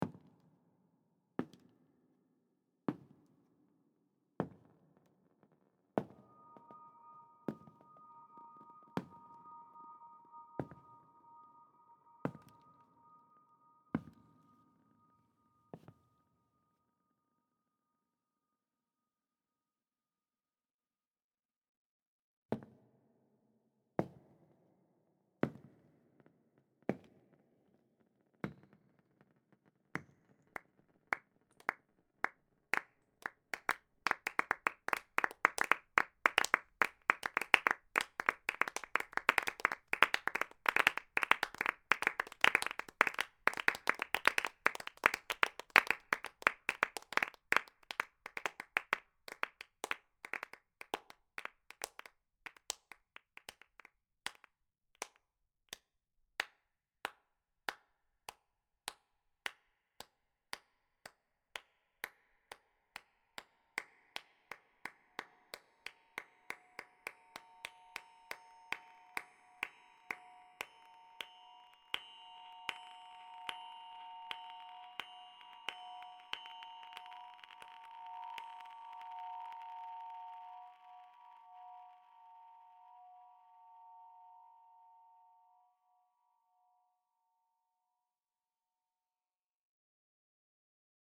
Compositional excerpts were created using a mixture of recognizable, real-world field recordings, processed recordings and synthetic pitched materials. Several excerpts exhibit phonographic approaches, while others involve sound-image transformations (i.e. sonic transmutation between two recognizable sounds) or interplay between synthetic pitched materials and concrète materials. The excerpts contained some repeating sounds — for instance, the sound of footsteps — while other sounds appeared only once.